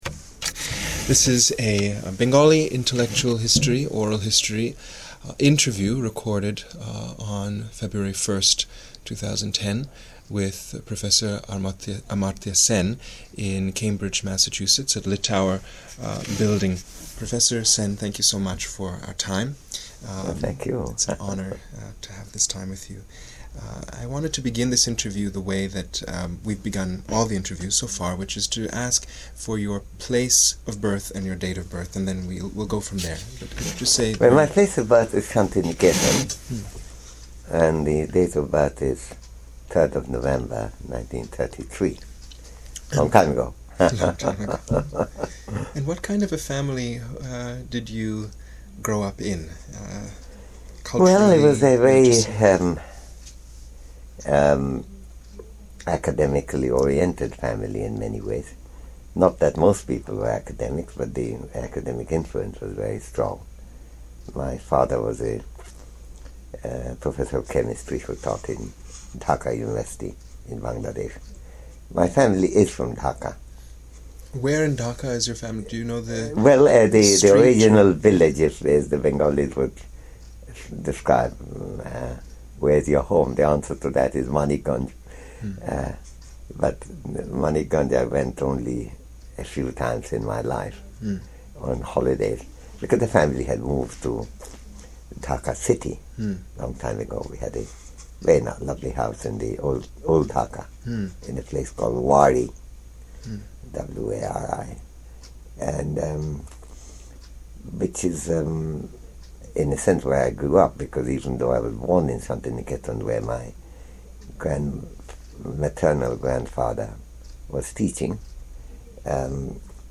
Oral history interview with Amartya Sen
Interviewed in Boston, Massachusetts, United States of America